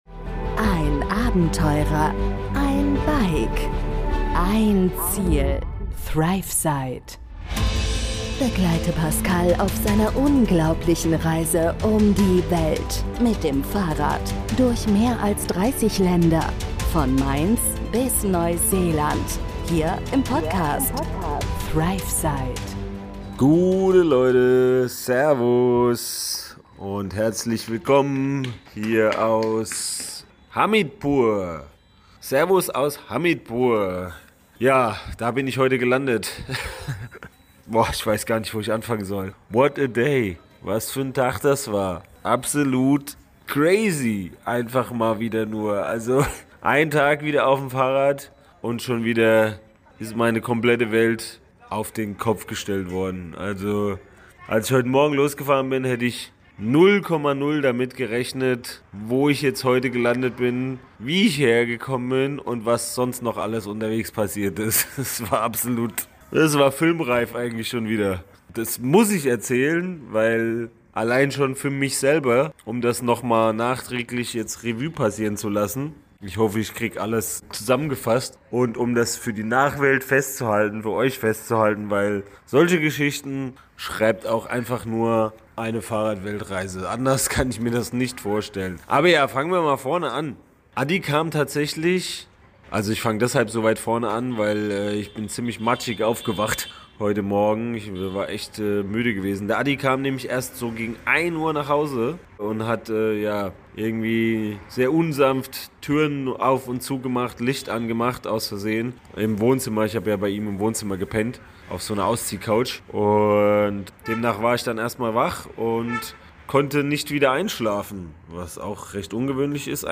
Hört rein für eine Achterbahnfahrt der Gefühle und spannende Geschichten direkt von der Straße!